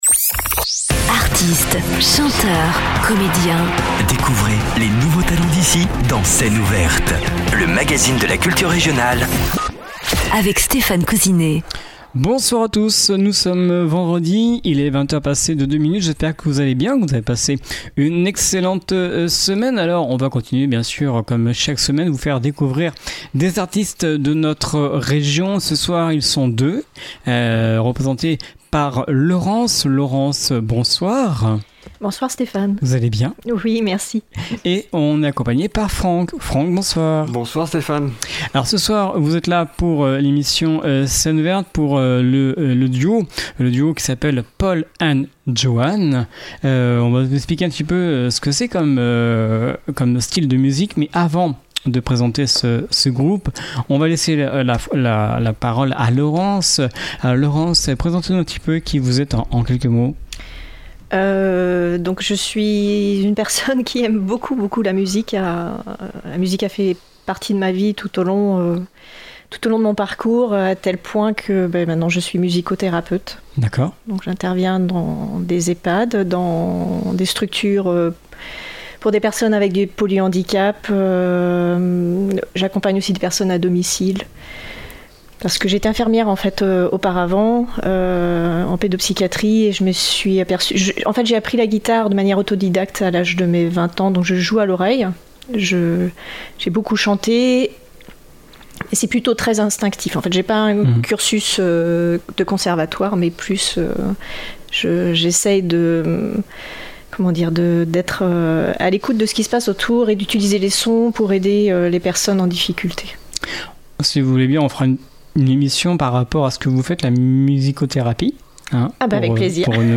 duo mixte acoustique rochelais